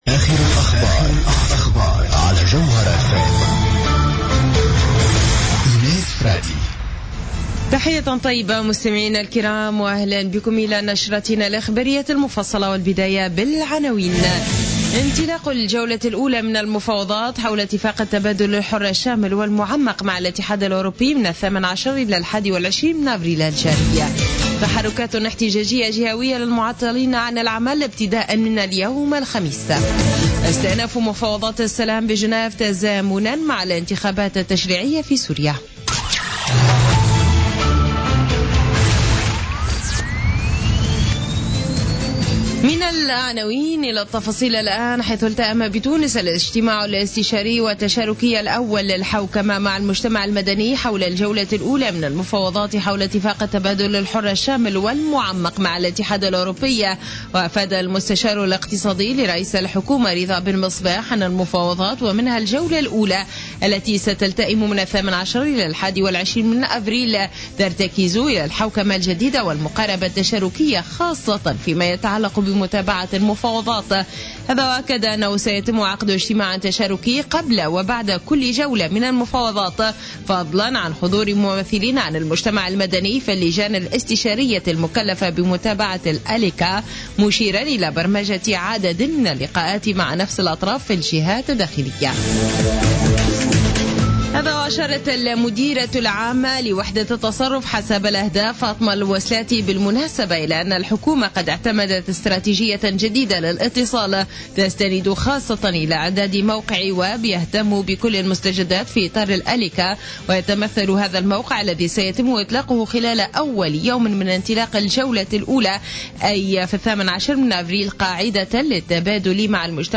نشرة أخبار منتصف الليل ليوم الخميس 14 أفريل 2016